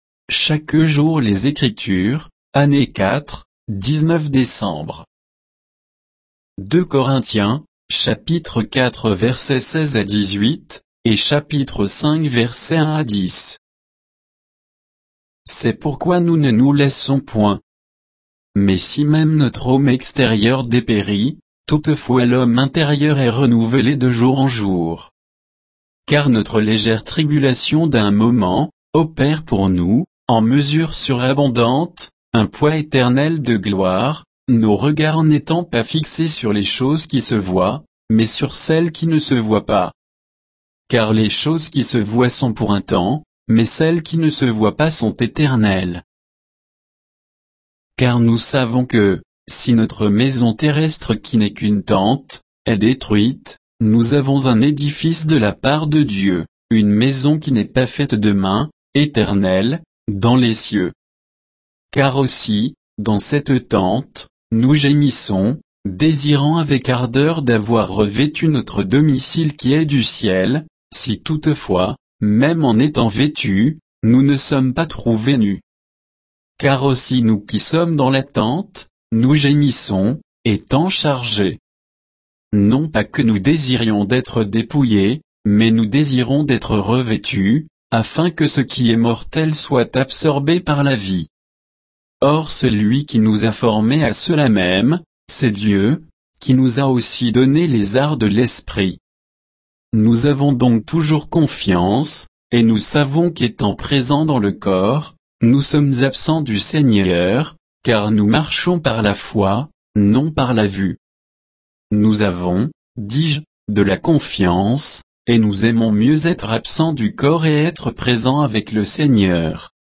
Méditation quoditienne de Chaque jour les Écritures sur 2 Corinthiens 4